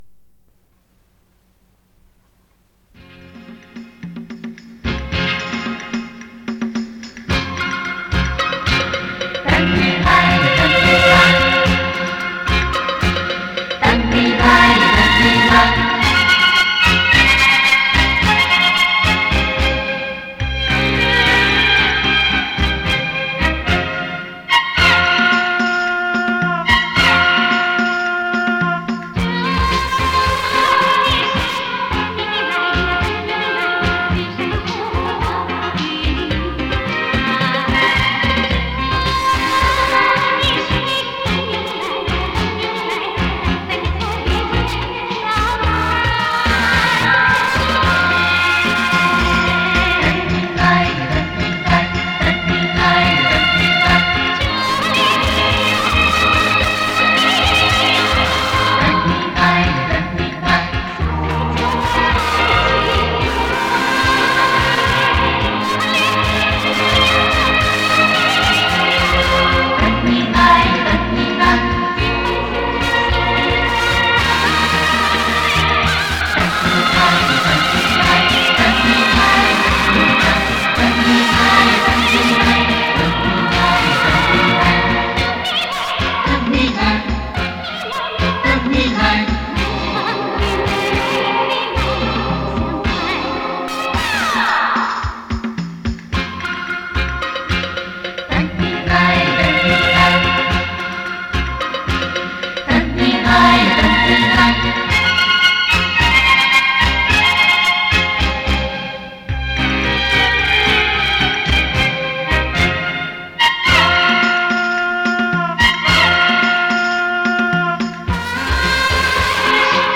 这首歌曲结奏欢快，充满了少数民族的气息。
她的歌喉清凉甜美，深受人们喜爱。